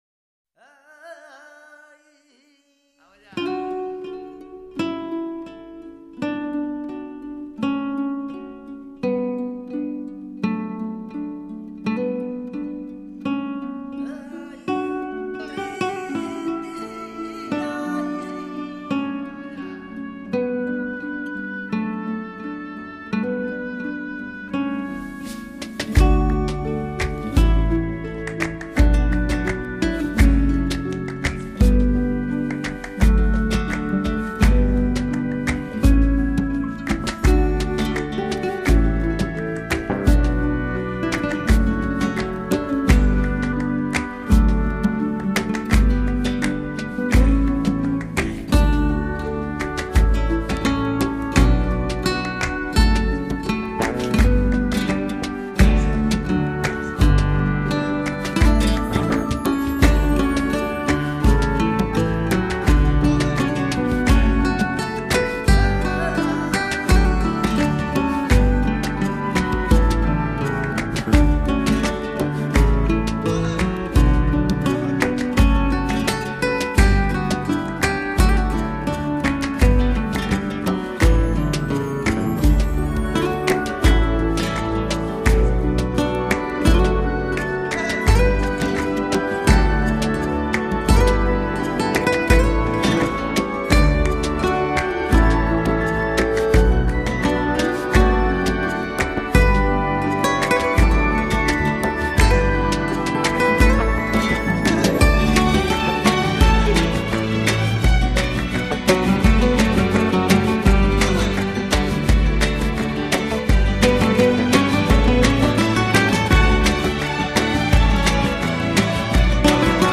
Guitar
一首首熟悉的旋律，一段段迷人的吉他，弗拉明戈和古典音乐的完美结合，演绎出不一样的经典，给我们不一样的音乐享受。